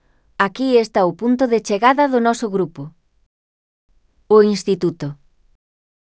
Elaboración propia (proxecto cREAgal) con apoio de IA, voz sintética xerada co modelo Celtia.